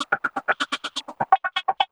Index of /90_sSampleCDs/Houseworx/12 Vocals/74 Processed Vocal Loops